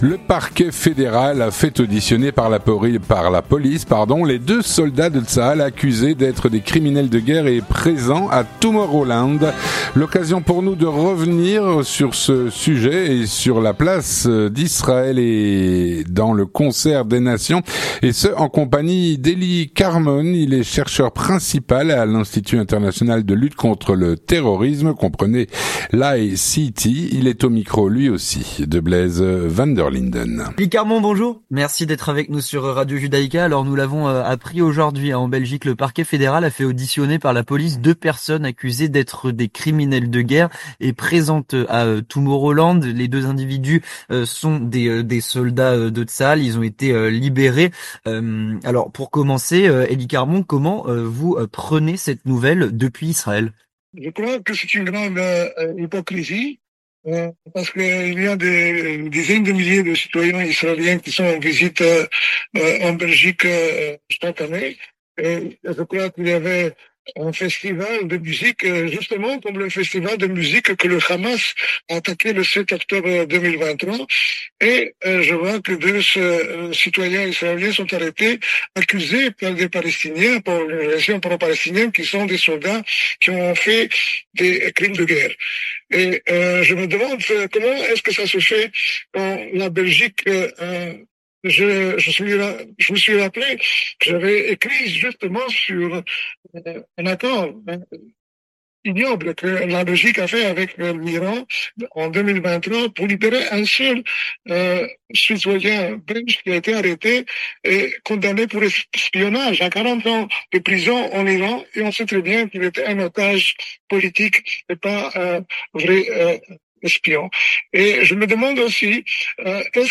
Il répons aux questions de